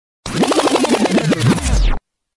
Fx Disco Rayado Sound Button - Free Download & Play